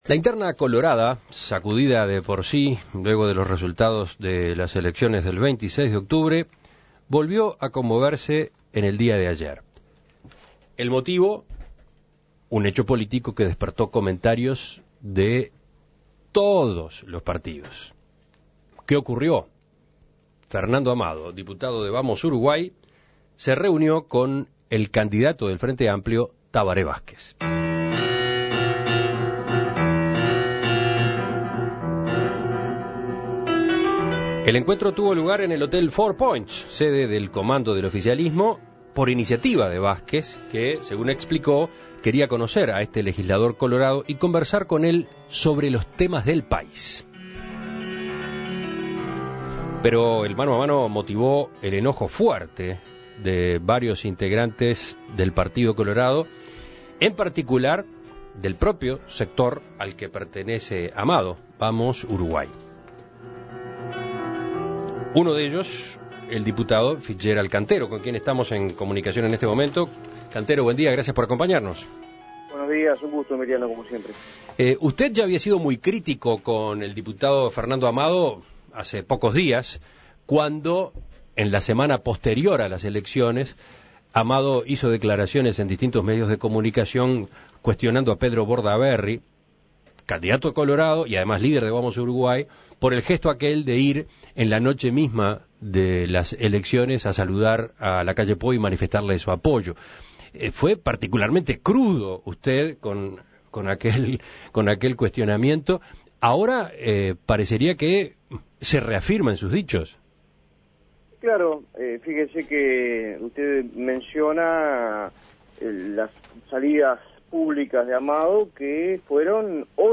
Así lo manifestó a En Perspectiva Fitzgerald Cantero, pro secretario general del Partido Colorado.